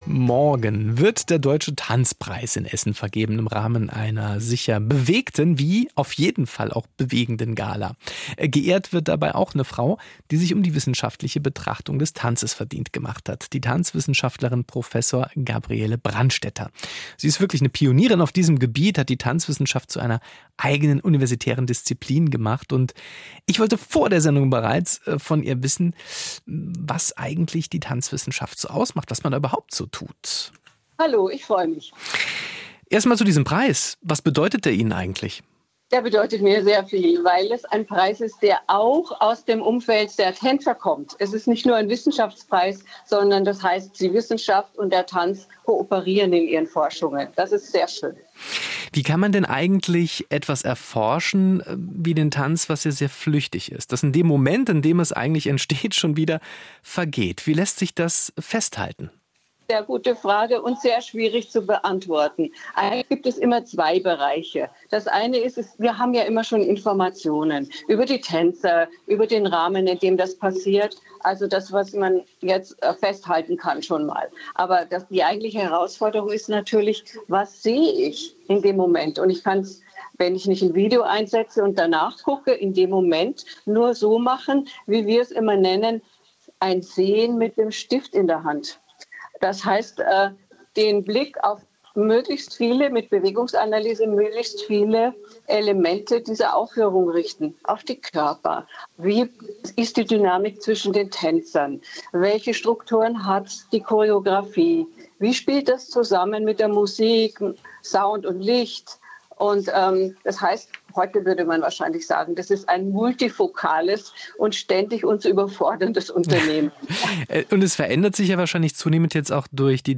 Interview mit Prof. Dr. Gabriele Brandstetter im Saarländischen Rundfunk